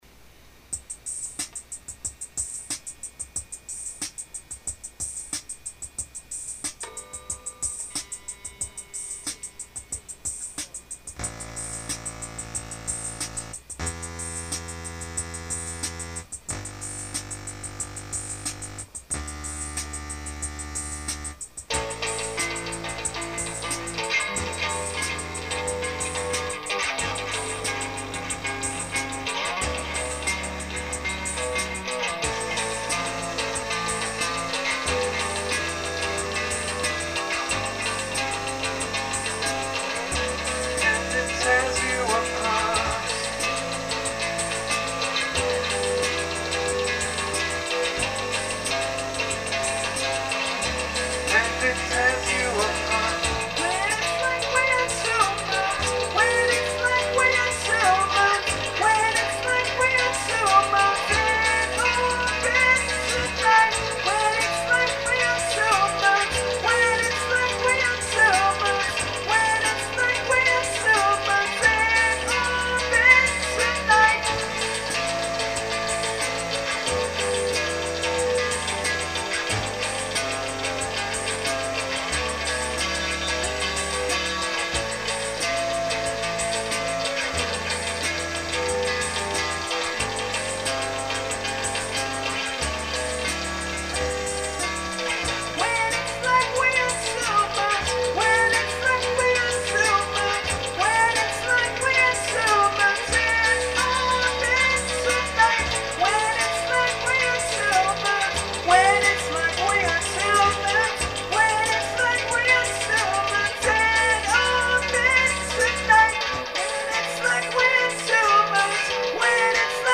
in_situ_-_third_song_demo.mp3